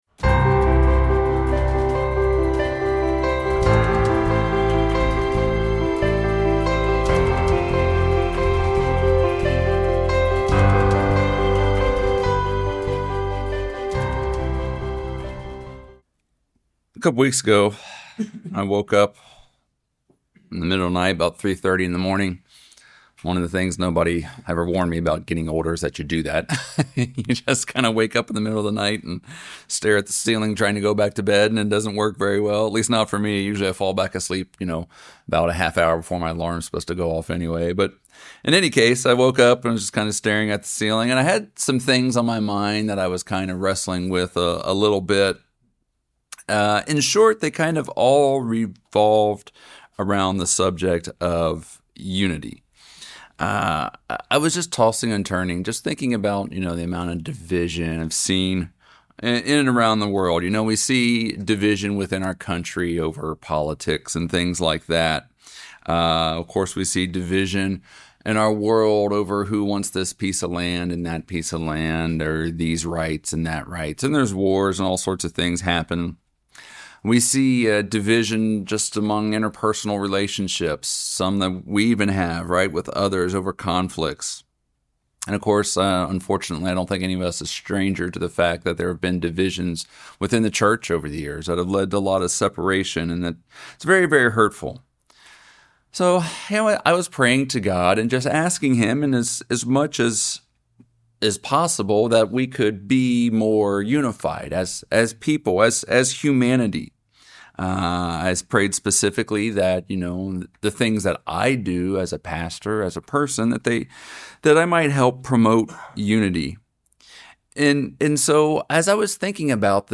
Given in Charlotte, NC Hickory, NC Columbia, SC